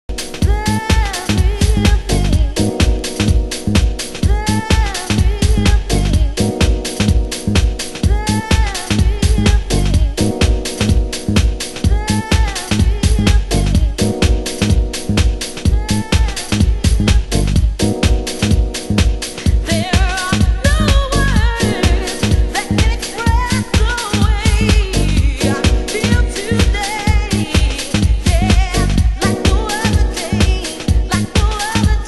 ○DEEP/DUB/DISCOなど、レーベル音源よりセレクトした良質な内容のコンピ！